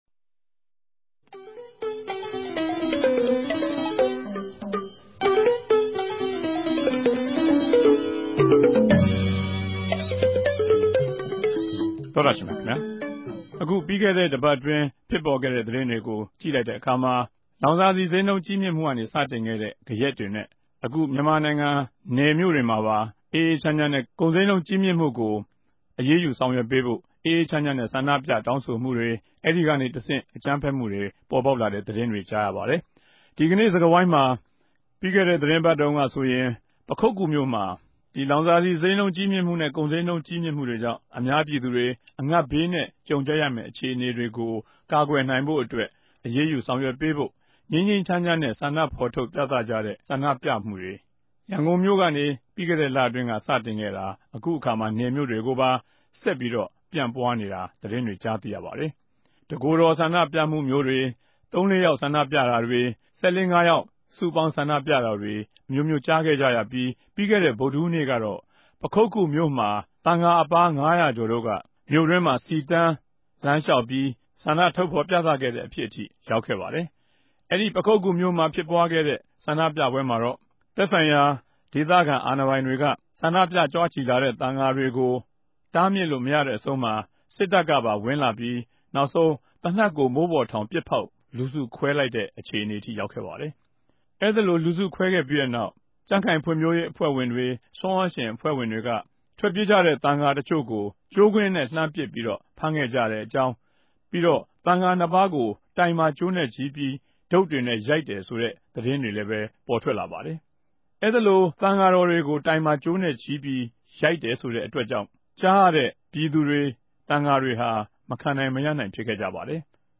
တနဂဿေိံြ ဆြေးေိံြးပြဲစကားဝိုင်း